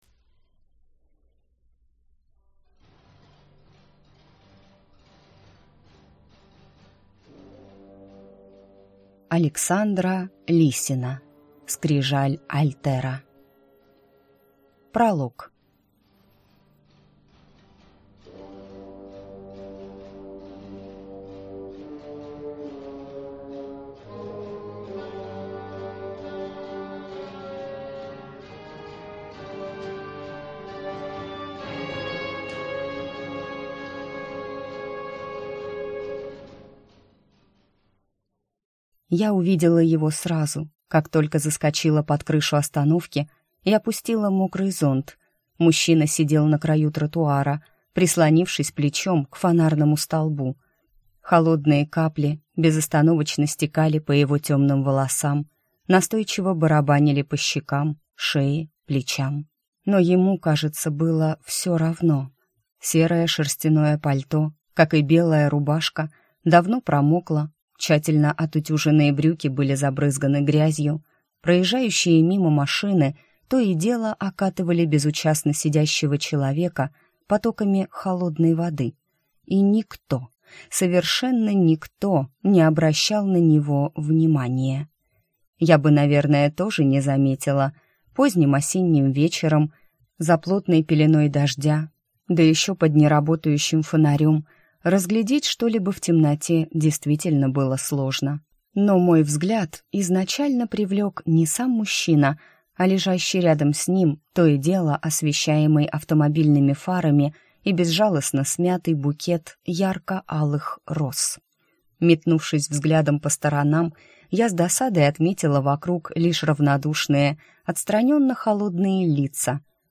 Аудиокнига Скрижаль альтера | Библиотека аудиокниг